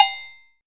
PERC.22.NEPT.wav